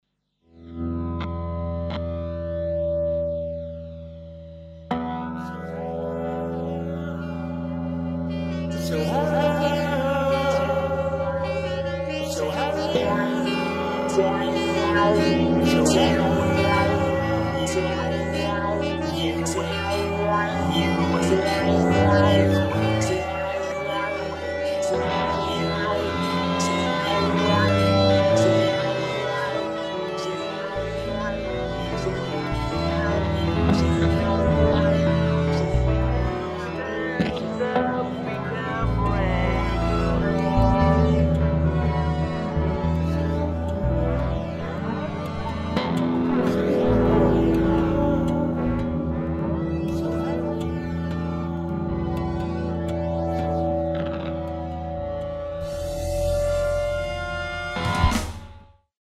vocals, electric guitars, bass, drums